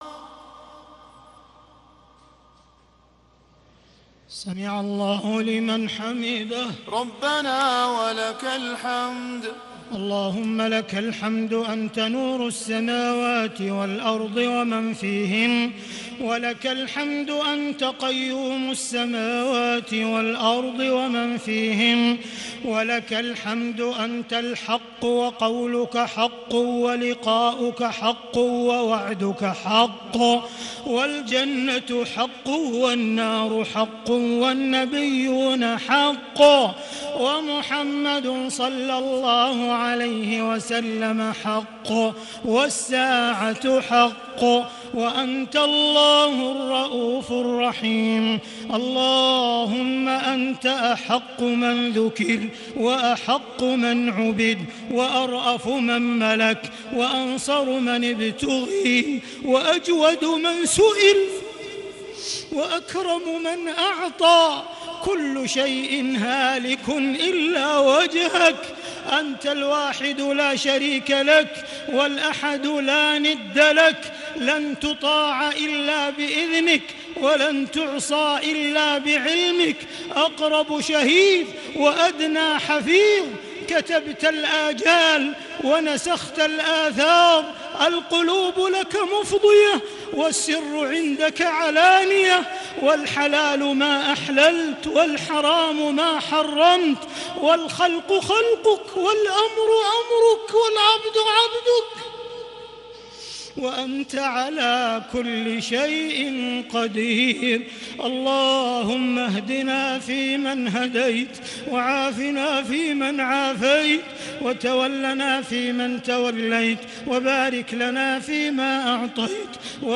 دعاء القنوت ليلة 25 رمضان 1440هـ | Dua for the night of 25 Ramadan 1440H > تراويح الحرم المكي عام 1440 🕋 > التراويح - تلاوات الحرمين